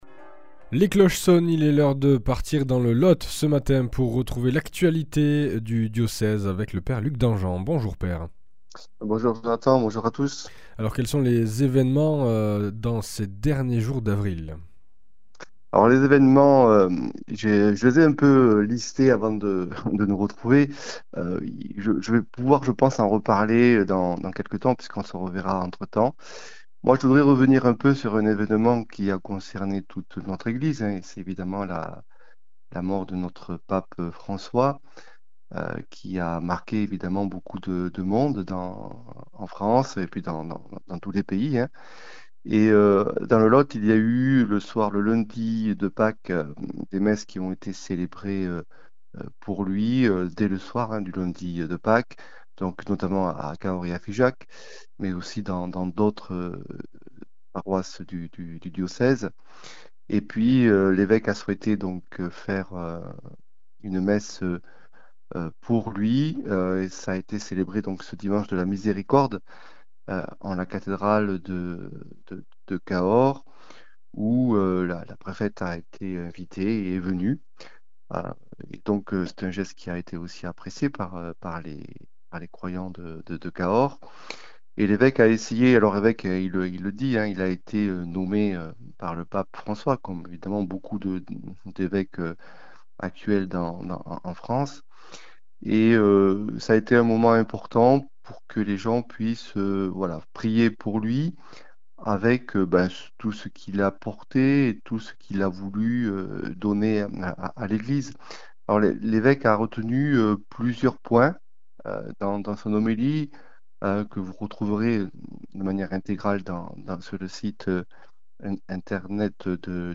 – Homélie de Mgr Laurent Camiade :